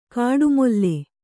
♪ kāḍu molle